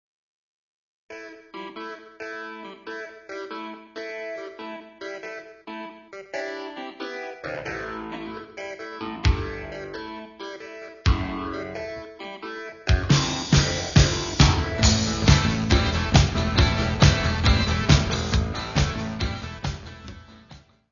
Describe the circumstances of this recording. : stereo; 12 cm (Live).